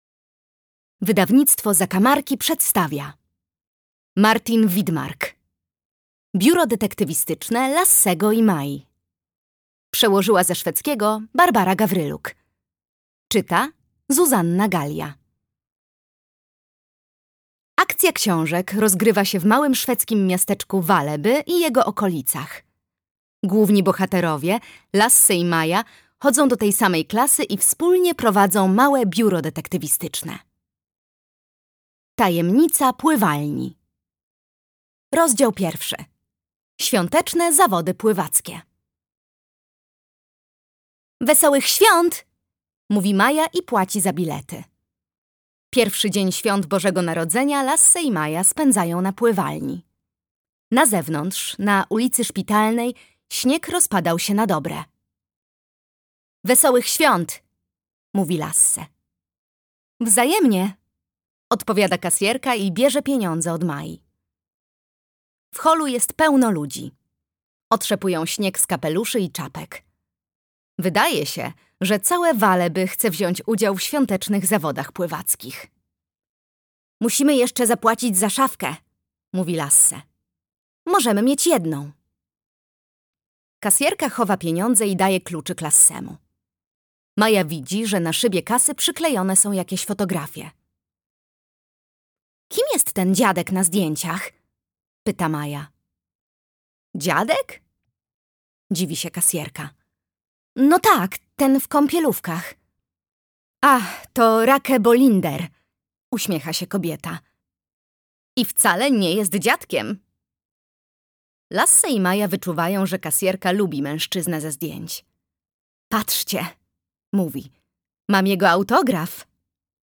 Biuro Detektywistyczne Lassego i Mai. Tajemnica pływalni - Martin Widmark - audiobook